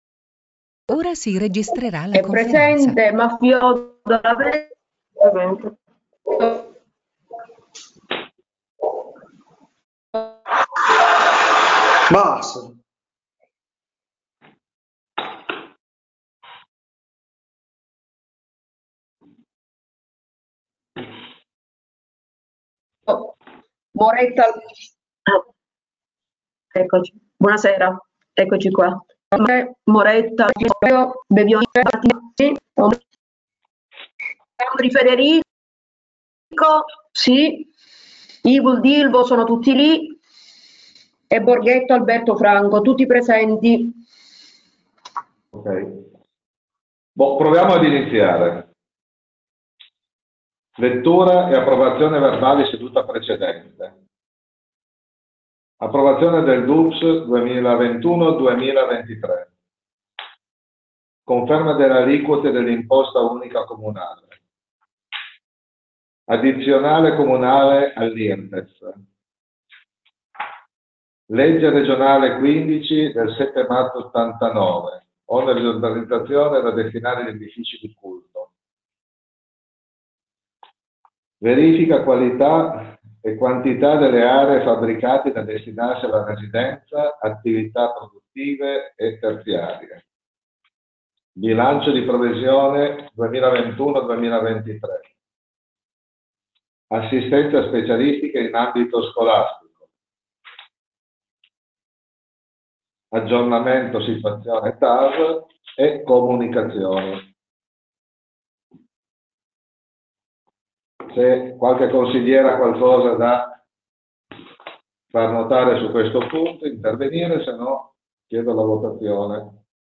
CONSIGLIO COMUNALE - Registrazioni sedute
Si pubblicano le registrazioni audio delle sedute svoltesi in modalità on-line.